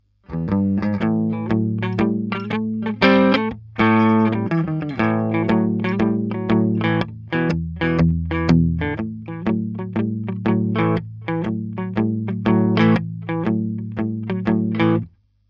Clean – Jazz, Blues, Pop, Funk, Country
Bei nicht vorhandenem oder kaum aufgedrehtem Gain nennt man einen Sound »clean« – eben sauber, ohne oder fast ohne Sättigung, Komprimierung oder Verzerrung.
Clean klingt die Gitarre in ihrem natürlichen Sound